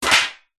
Звуки кастрюли, сковороды
Звон металлической посуды, грохот падения на кухонный пол